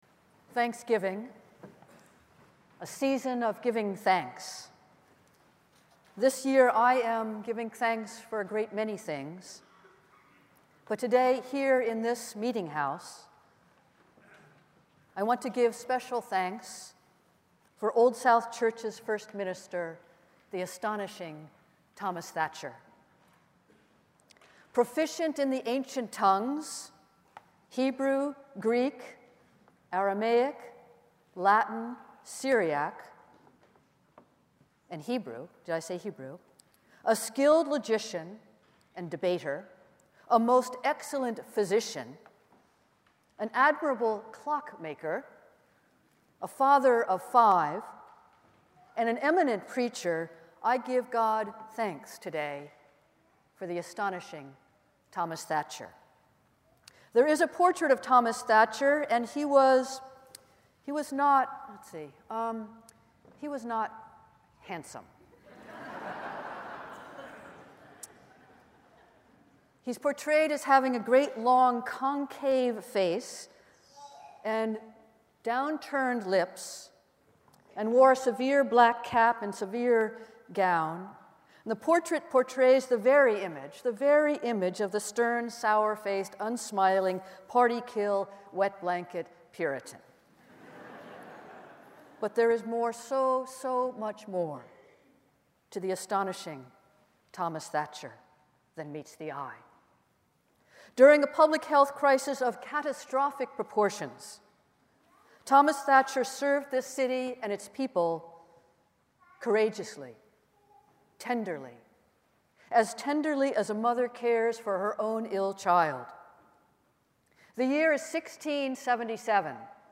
Festival Worship - Meeting House Sunday